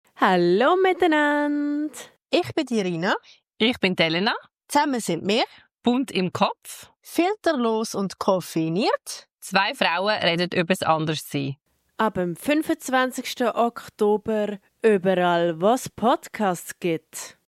Filterlos & koffeiniert – zwei Frauen reden übers Anderssein